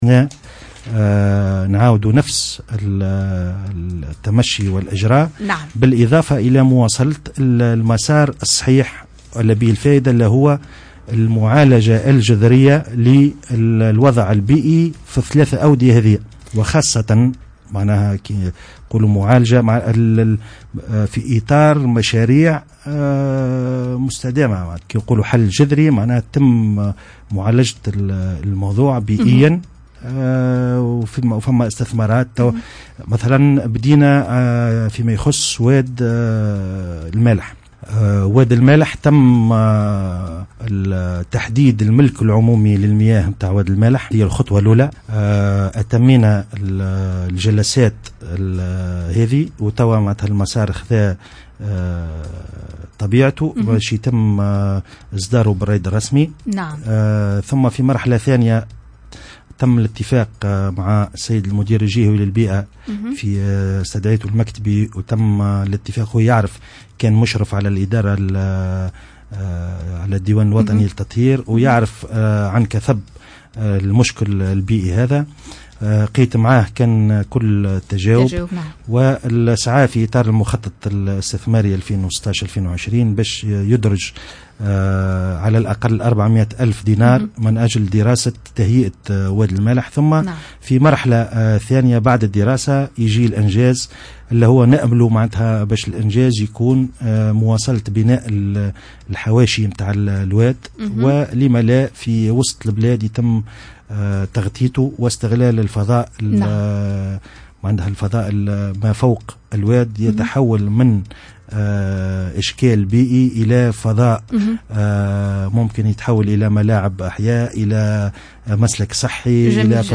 تصريح المعتمد